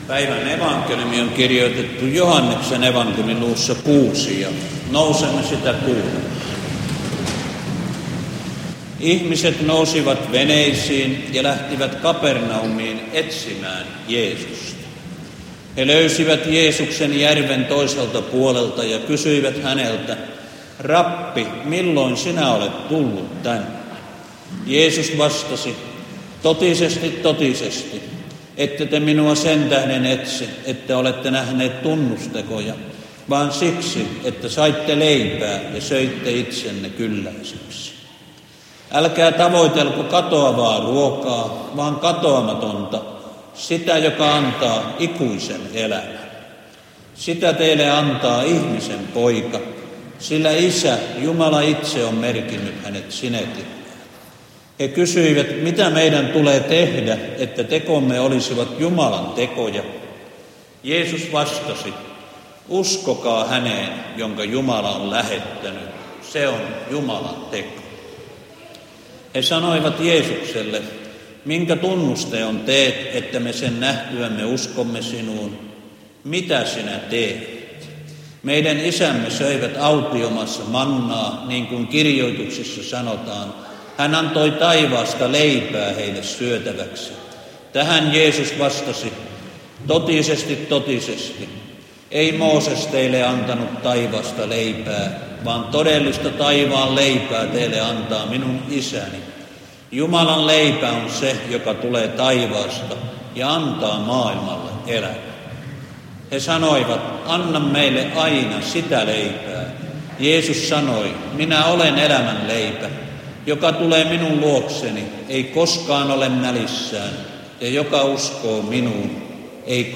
Veteli